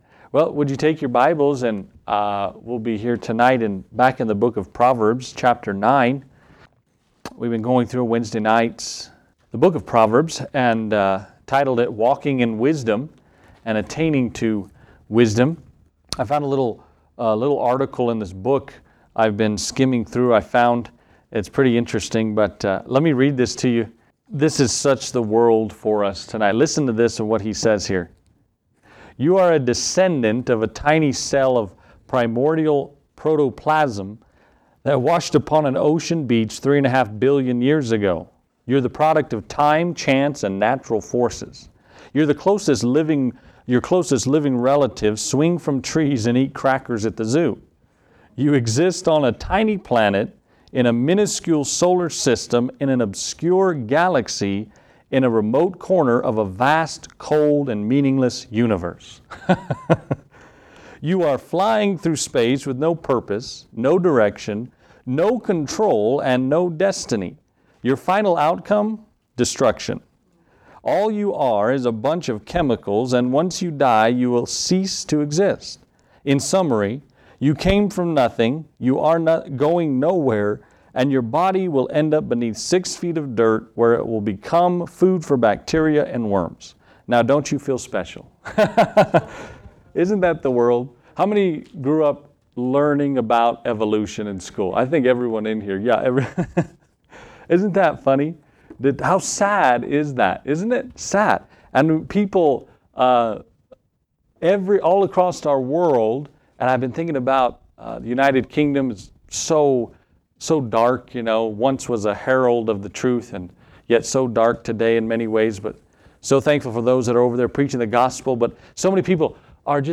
Wisdom that transcends all generations has been given to us in the Word of God. In this message, we look at Proverbs 9 and how to attain God’s wisdom from His Word.